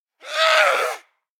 DayZ-Epoch/SQF/dayz_sfx/zombie/chase_11.ogg at 60177acd64446dce499ec36bbd9ae59cdc497fff
chase_11.ogg